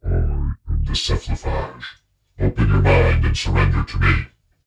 (alpha_male starts then vocoder).
But the sound of the vocoder only appears a second after the sound has been started.
It appears abruptly.
alien_andro.wav